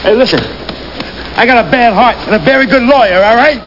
Amiga 8-bit Sampled Voice
oldwoman.mp3